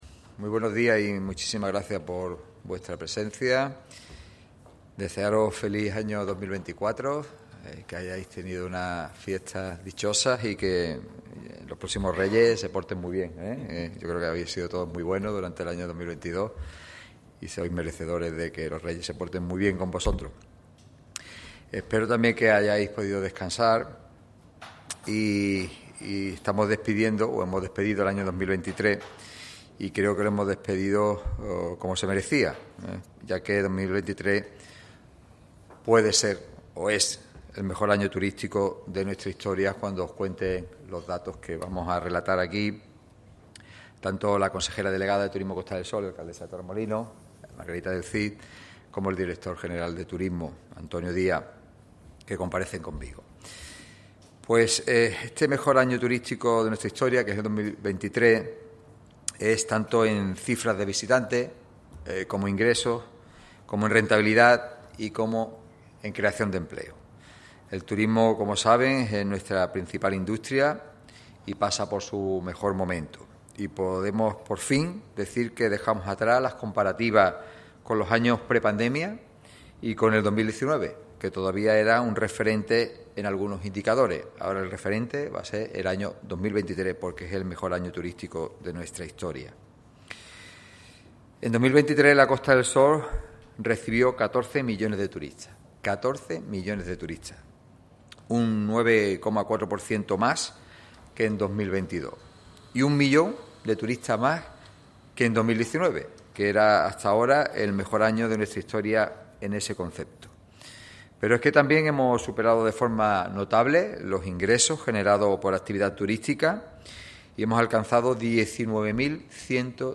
Así lo ha puesto de manifiesto el presidente de la Diputación y de Turismo Costa del Sol, Francisco Salado, en la rueda de prensa en la que ha presentado el balance del año.